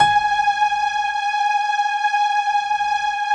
SI1 PIANO0DR.wav